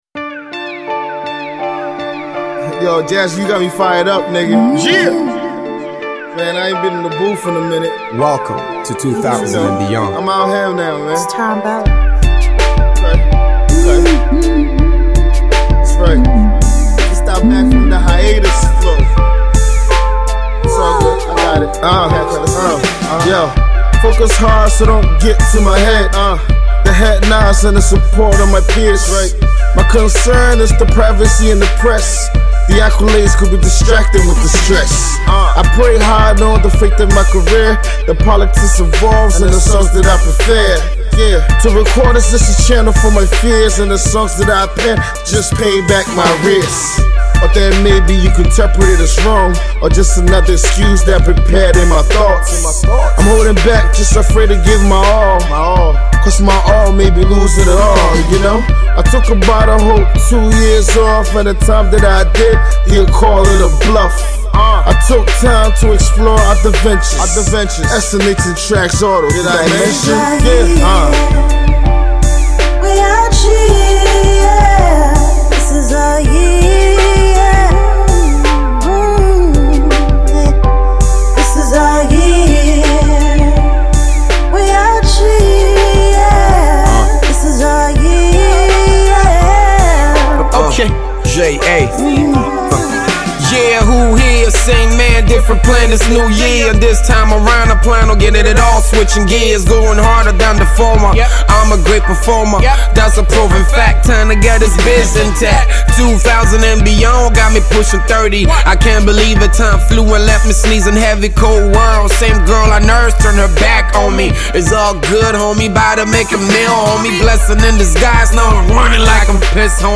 Hip-Hop
Triumphant Rap Single